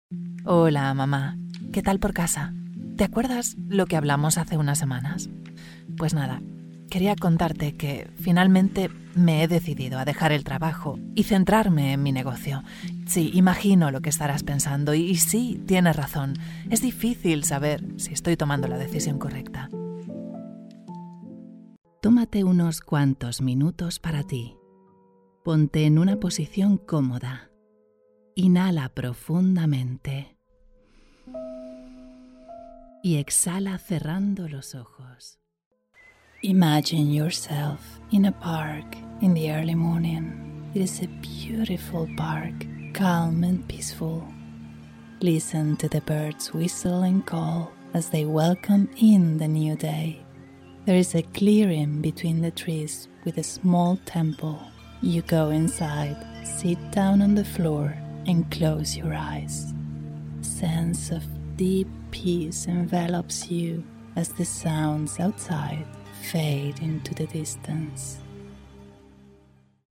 WARM & HUMAN
BRAND NARRATION
Professional Home studio with specific Voiceover equipment.
– Sennheiser MK4 microphone
CALM-WARM-HUMAN.mp3